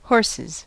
Ääntäminen
Ääntäminen US Tuntematon aksentti: IPA : /hɔəsəs/ IPA : /ˈhɔɹ.sɪz/ IPA : /ˈhɔː.sɪz/ Haettu sana löytyi näillä lähdekielillä: englanti Käännöksiä ei löytynyt valitulle kohdekielelle. Horses on sanan horse monikko.